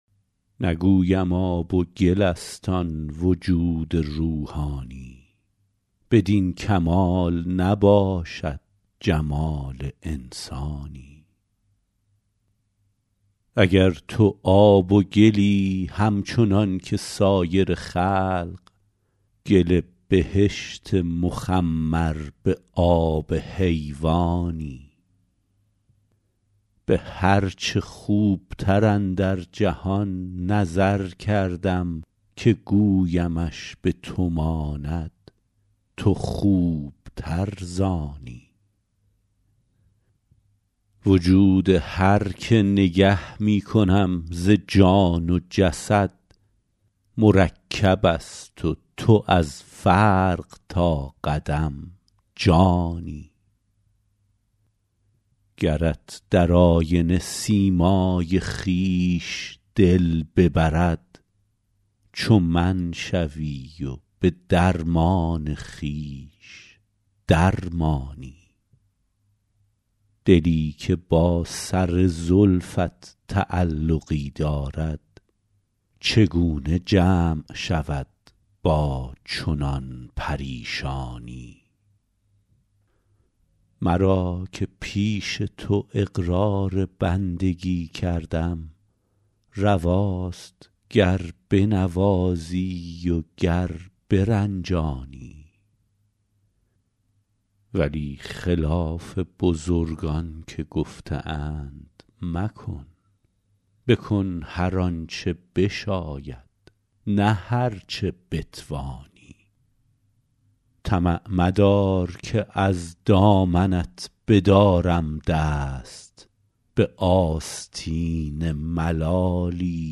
غزل شمارهٔ ۶۱۶ به خوانش